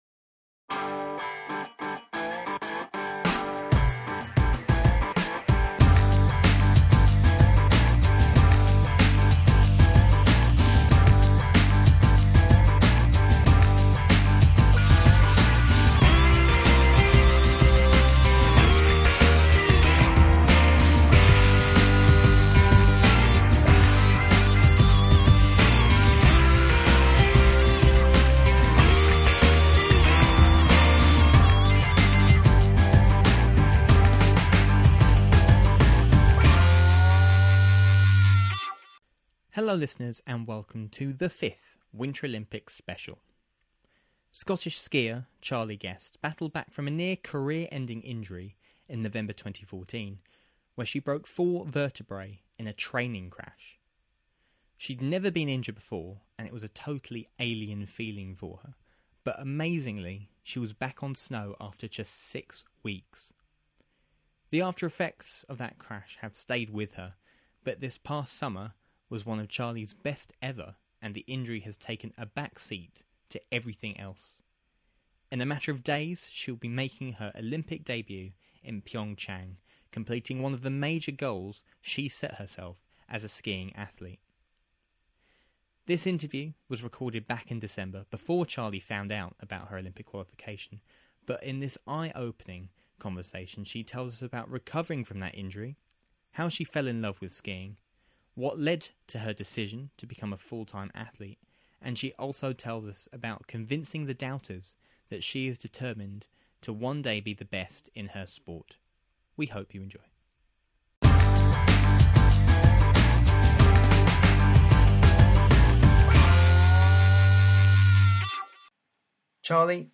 On this episode alpine skier Charlie Guest chats about her journey to get to her first Olympics after suffering a career-threatening injury in 2014.